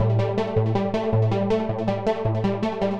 Index of /musicradar/future-rave-samples/160bpm
FR_EeePad_160-A.wav